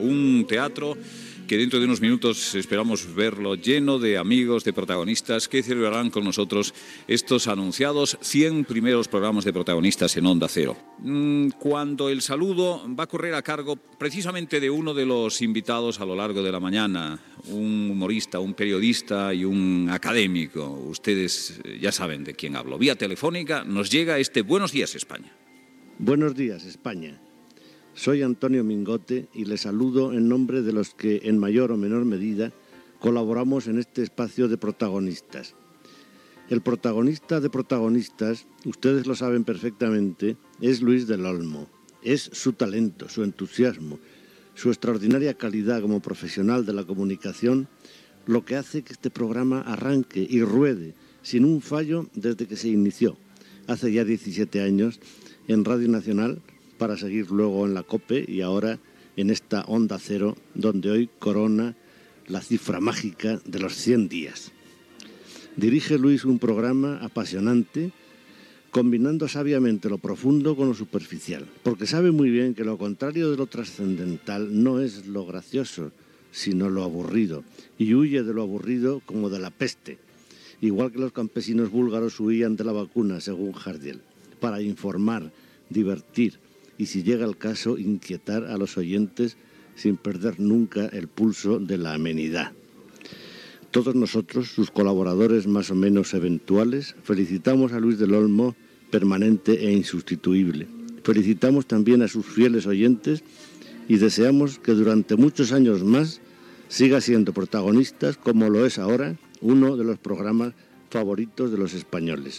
Programa númeo 100 a Onda Cero i salutació de l'humorista Antonio Mingote a la secció "Buenos días España"
Info-entreteniment